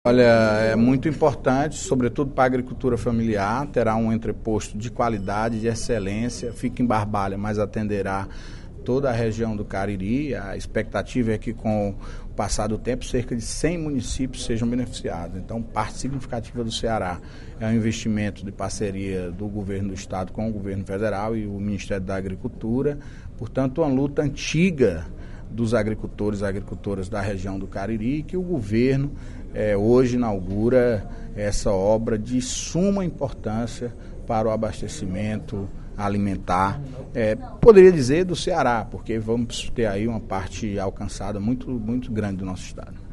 O líder do Governo na Assembleia Legislativa, deputado Antonio Carlos (PT), destacou, na sessão plenária desta quinta-feira (23/02) da Assembleia Legislativa, a inauguração do posto das Centrais de Abastecimento do Ceará S/A (Ceasa) na região do Cariri.